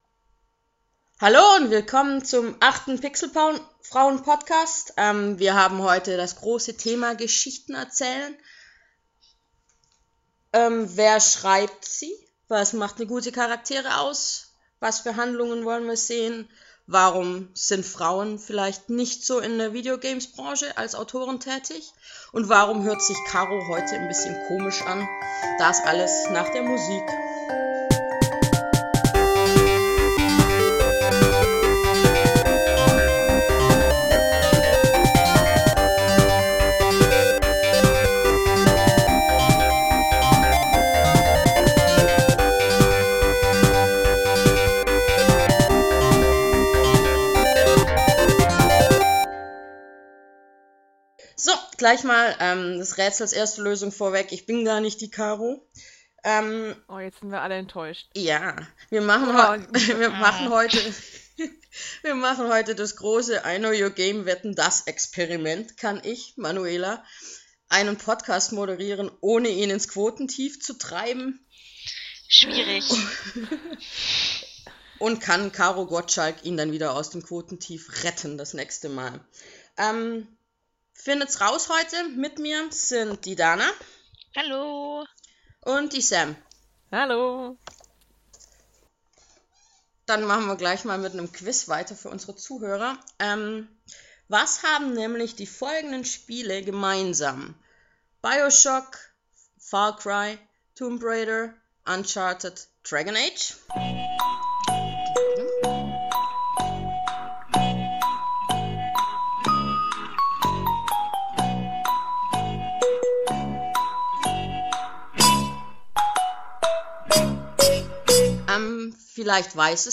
Pixelfrauen: Der Frauenplausch Folge 8 - Frauengeschichten